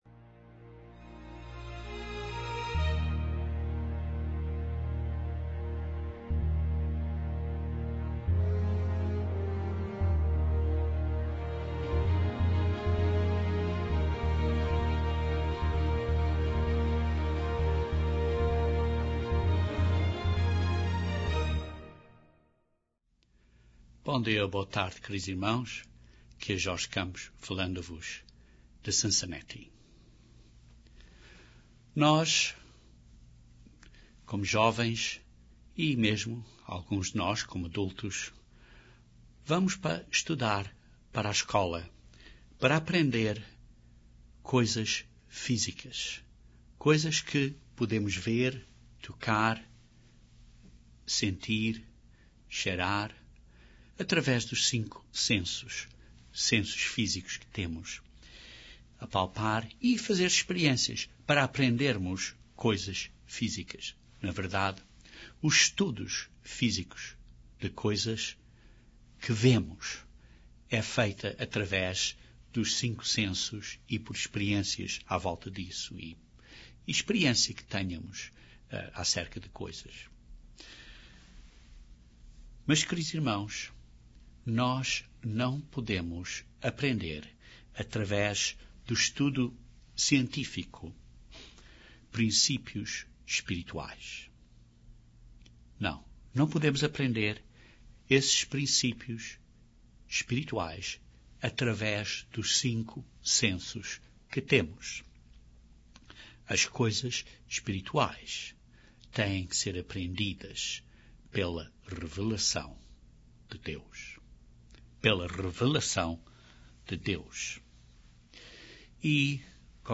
Este sermão descreve algumas bençãos da água e extrai princípios espirituais destas lições.